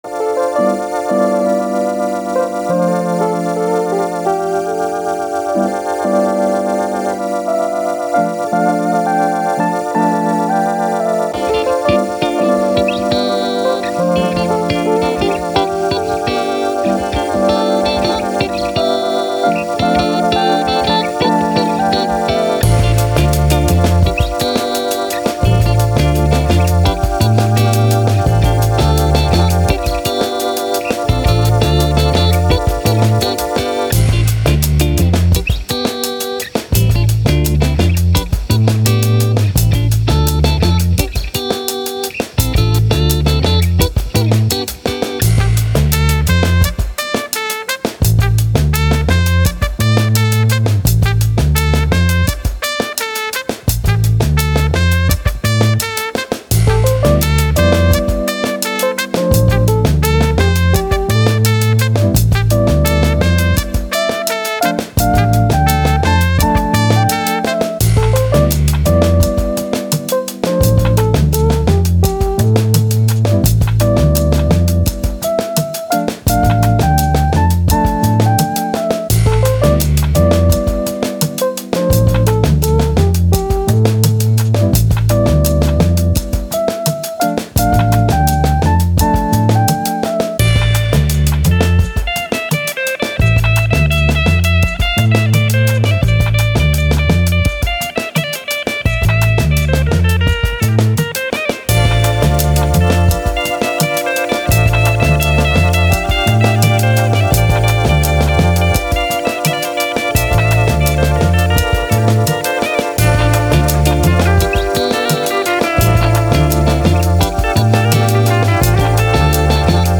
Afrobeat, Jazz, Sun, Story, Positive, Upbeat